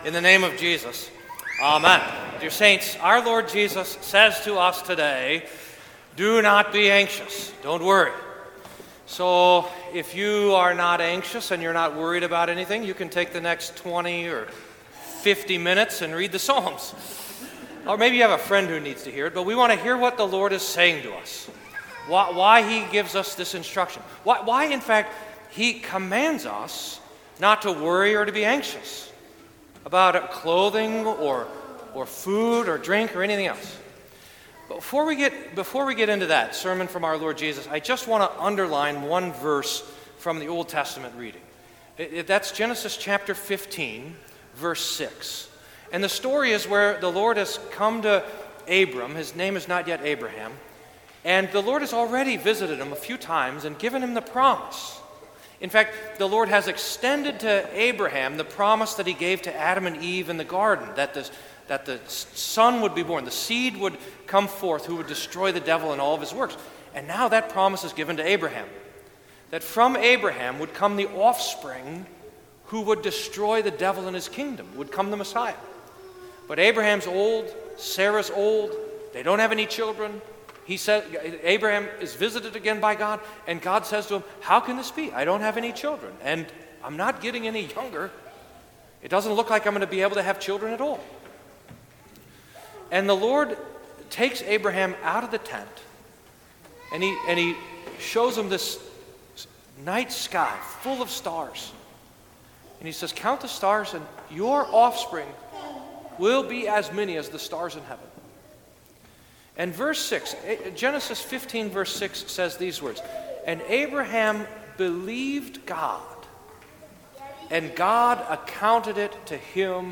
Sermon for Ninth Sunday after Pentecost